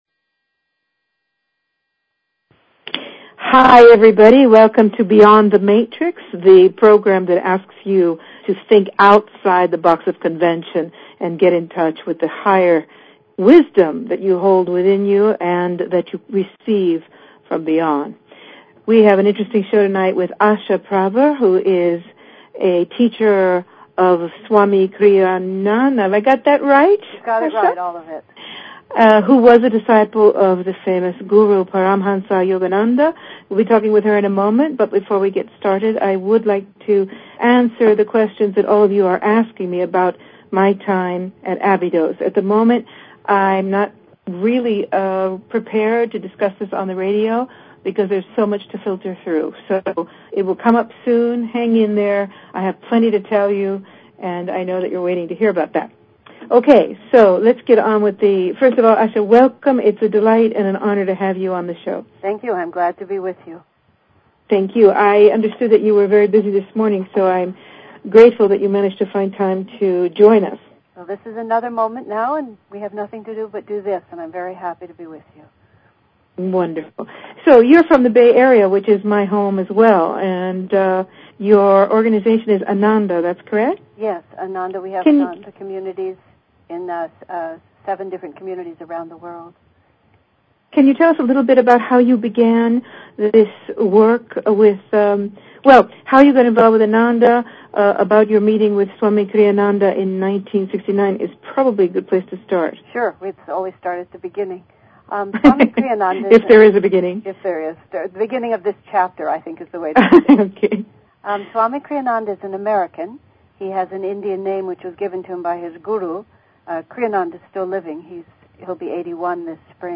Talk Show Episode, Audio Podcast, Beyond_The_Matrix and Courtesy of BBS Radio on , show guests , about , categorized as